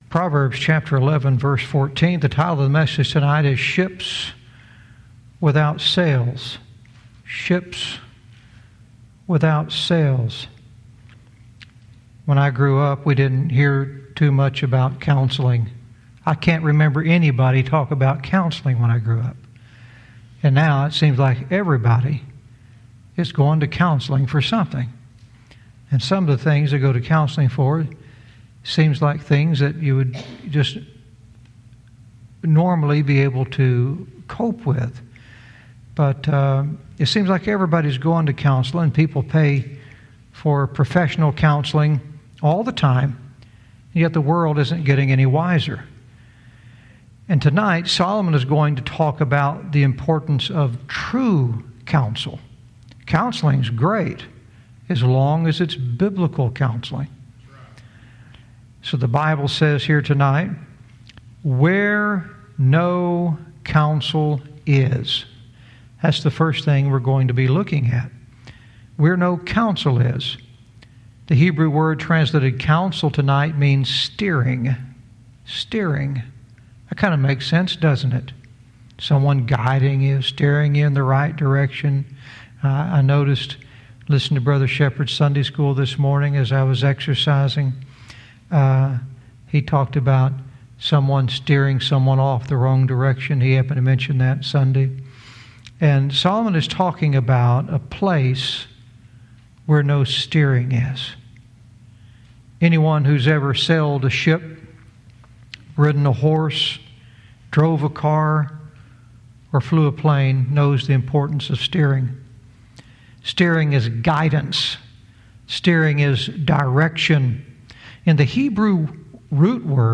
Verse by verse teaching - Proverbs 11:14 "Ships Without Sails"